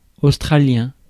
Ääntäminen
Ääntäminen France: IPA: /ɔs.tʁa.ljɛ̃/ Tuntematon aksentti: IPA: /os.tʁa.ljɛ̃/ Haettu sana löytyi näillä lähdekielillä: ranska Käännös Substantiivit 1.